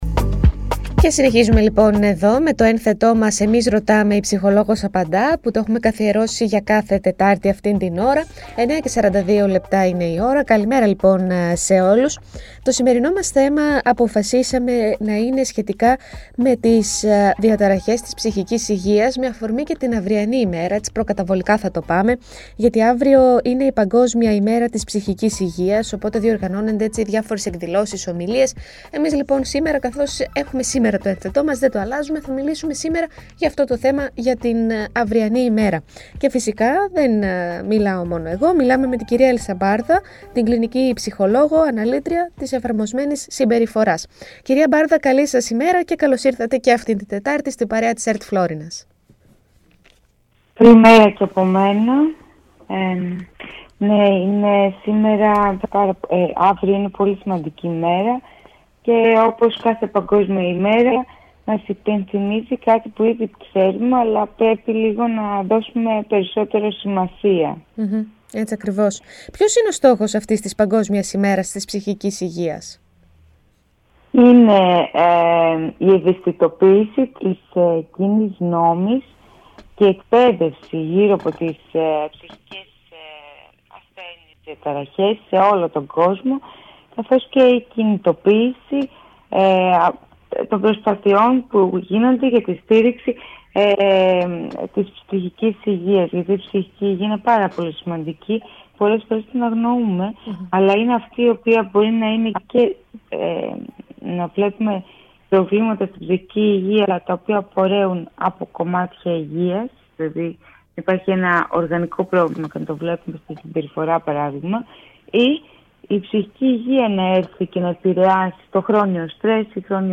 Συζητώντας για το εάν στη σημερινή κοινωνία υπάρχει αύξηση των προβλημάτων ψυχικής υγείας, αναφέραμε ποια είναι τα πιο συχνά προβλήματα και εστιάσαμε στην κατάθλιψη. Μιλώντας για την κατάθλιψη, η ψυχολόγος σημείωσε ορισμένα συμπτώματα, αλλά και αίτια που μπορούν να την προκαλέσουν.